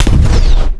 fire_capship.wav